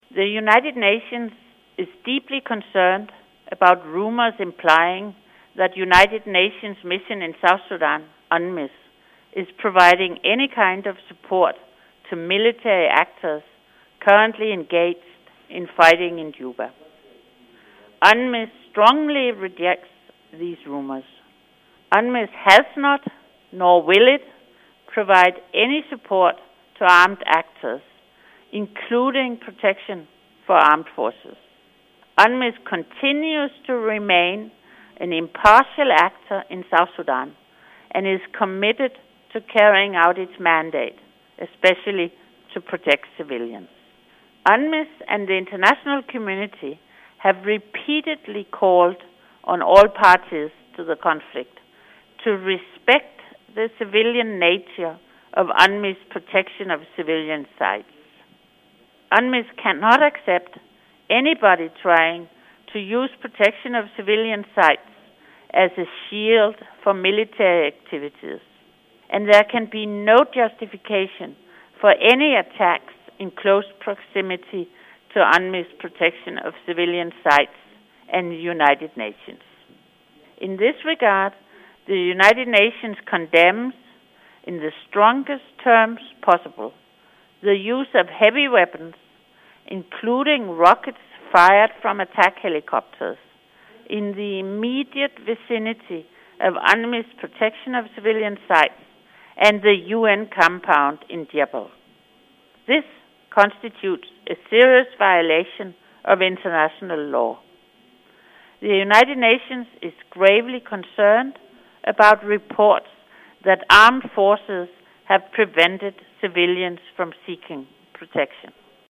In her special address Madam Loej stressed that UNMISS remains impartial and dedicated to its mandate in South Sudan